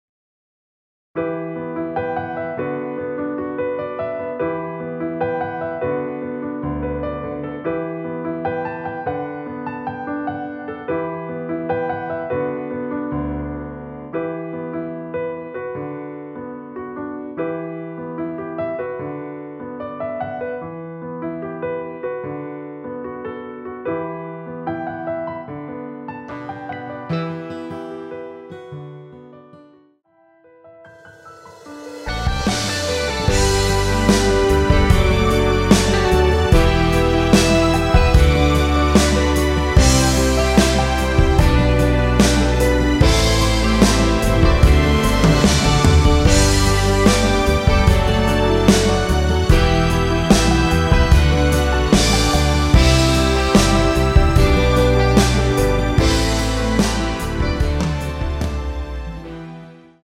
원키에서(-7)내린 MR입니다.
Em
앞부분30초, 뒷부분30초씩 편집해서 올려 드리고 있습니다.